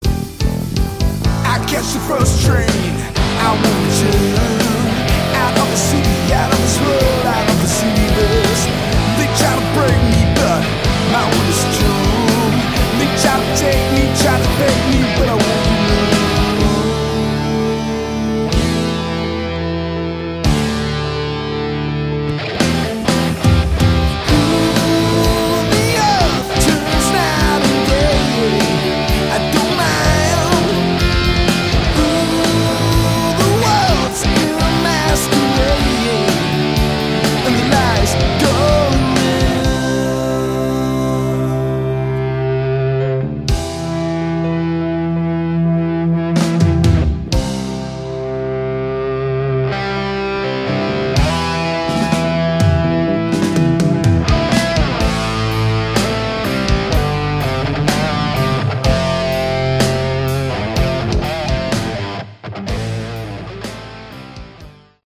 Category: Hard Rock
guitar, vocals
keyboards, Hammond organ